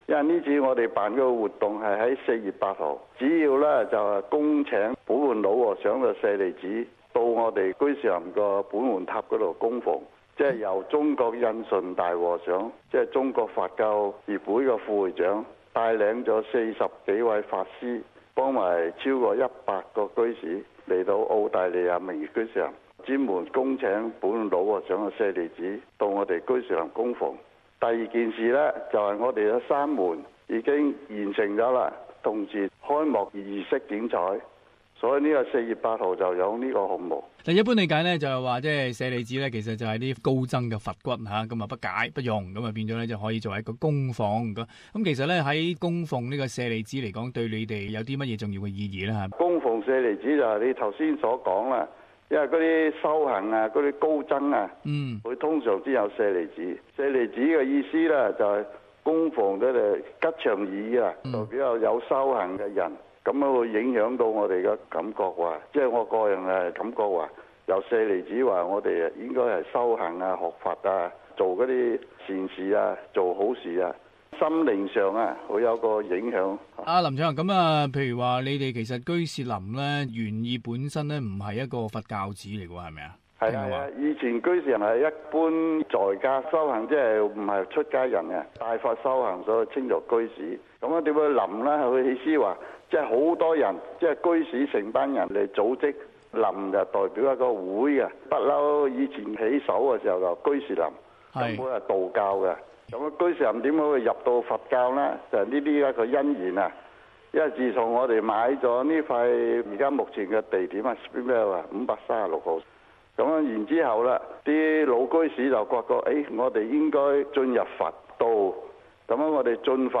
【社團專訪】墨爾本居士林迎接供奉本焕老和尚舍利子